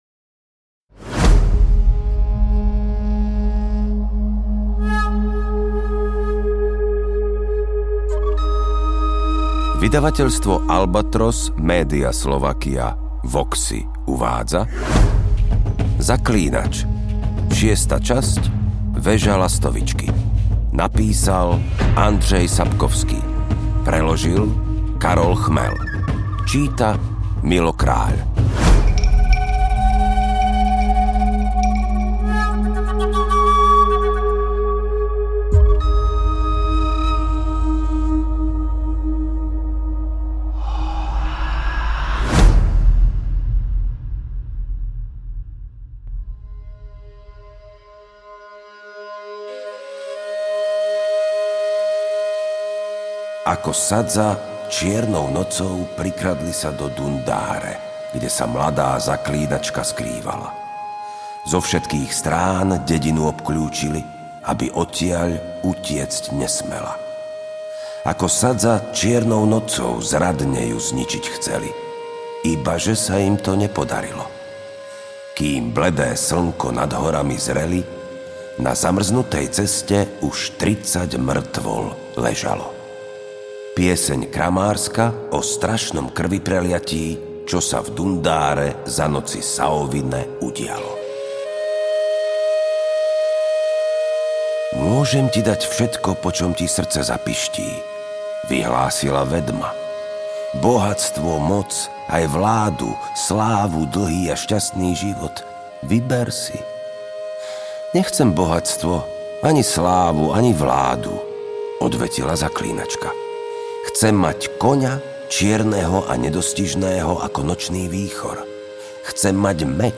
AudioKniha ke stažení, 69 x mp3, délka 19 hod. 51 min., velikost 1097,8 MB, slovensky